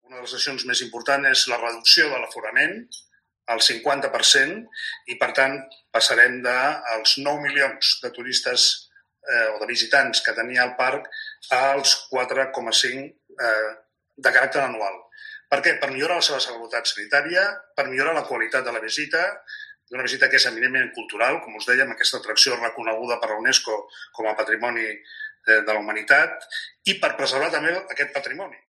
Corte de Jaume Collboni, primer teniente de alcalde y presidente del PSC en el Ayuntamiento de Barcelona